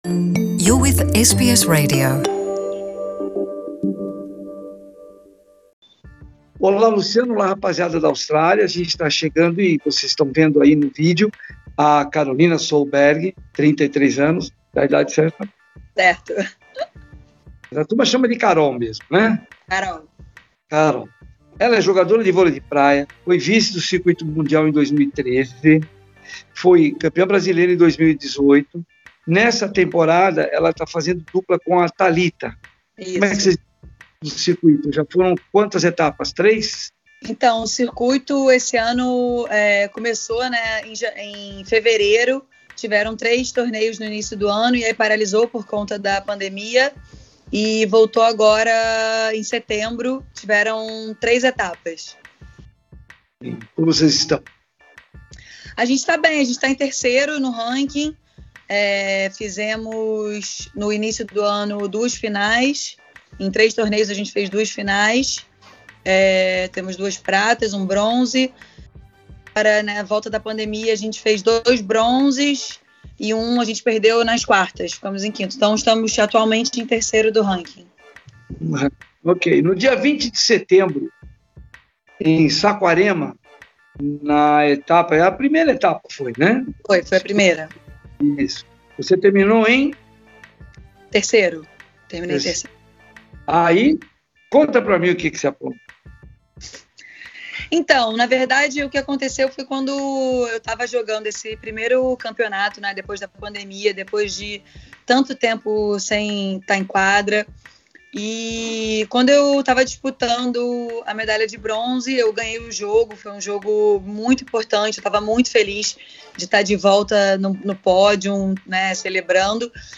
Nesta entrevista ela conta como virou celebridade depois deste gesto. E fala sobre os dois julgamentos no STJD onde ela foi considerada culpada na primeira vez e depois, no recurso, inocentada.